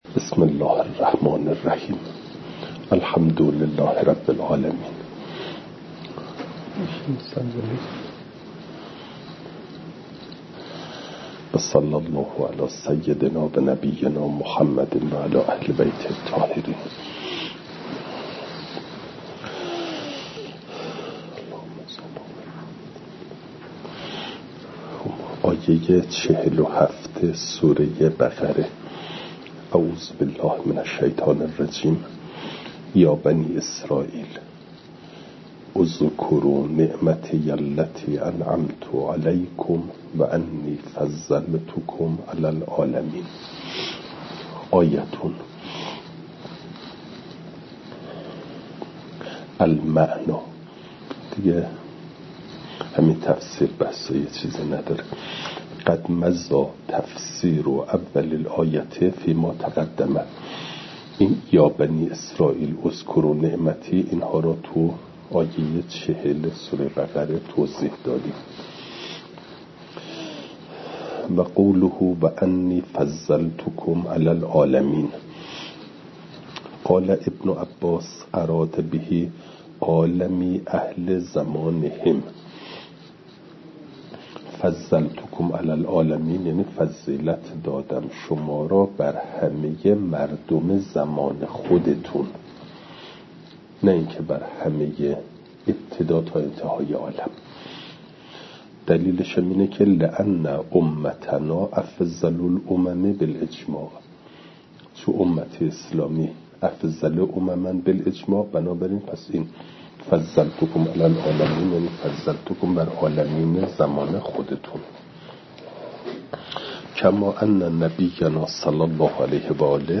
فایل صوتی جلسه پنجاه و هشتم درس تفسیر مجمع البیان